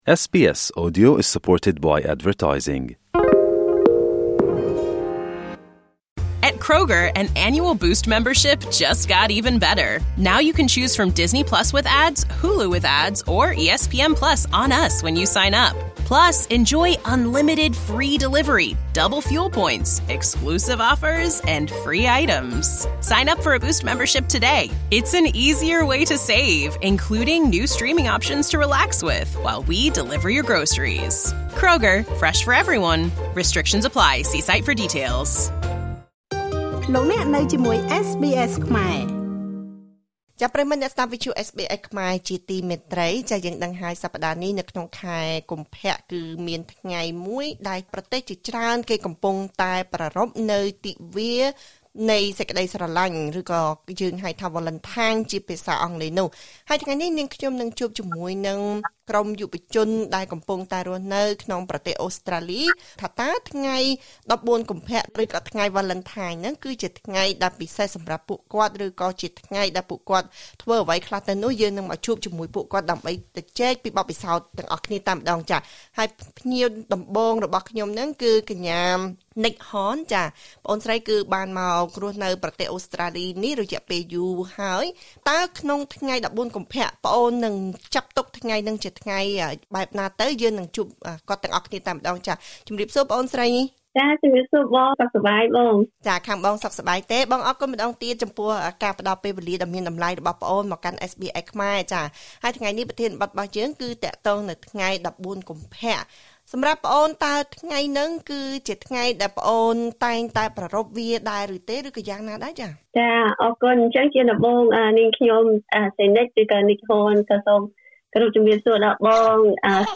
ប្រទេសជាច្រើនបានកំណត់យកថ្ងៃ១៤ ខែកុម្ភៈ ជាទិវានៃក្តីស្រឡាញ់ ចំណែកយុវជនមួយចំនួនបានចាត់ទុកថ្ងៃនេះជាថ្ងៃបុណ្យសង្សារ។ តើយុវជនកម្ពុជាអូស្រ្តាលីប្រារព្ធទិវានៃក្តីស្រឡាញ់នេះបែបណាដែរ? សូមស្តាប់កិច្ចសម្ភាសន៍ជាមួយយុវតីកម្ពុជាអូស្រ្តាលី ៣ រូប ជុំវិញការប្រារព្ធទិវានៃក្តីស្រឡាញ់។